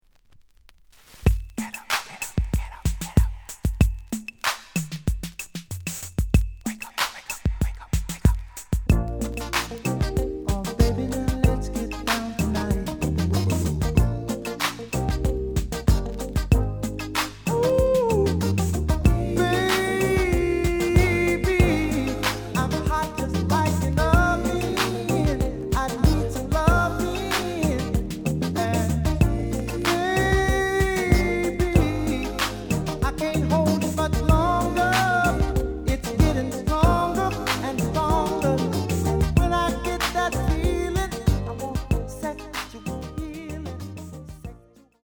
The audio sample is recorded from the actual item.
●Genre: Soul, 80's / 90's Soul
Some noise on beginning of A side.)